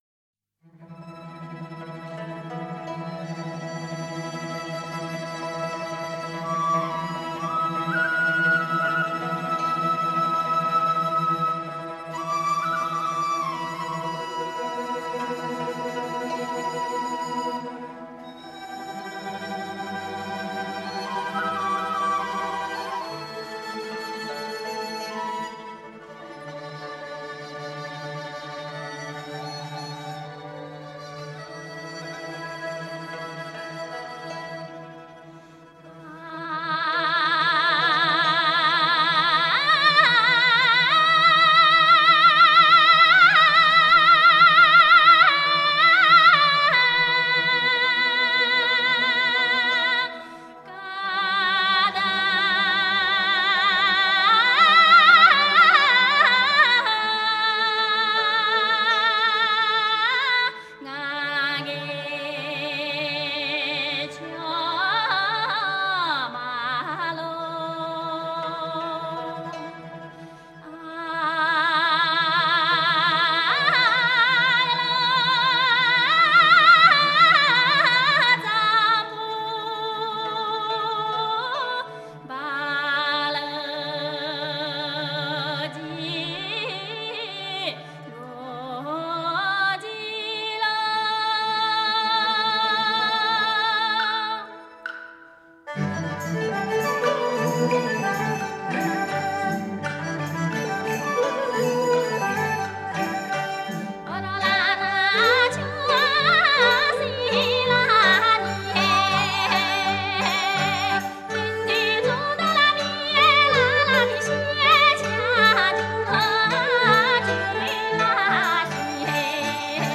少数民族音乐系列
29首歌，旋律朴实悦耳，歌声高吭开怀，
充份展现厡野牧民和农村纯朴，直率奔放的民风。
都有嘹亮的歌声，一流的技巧。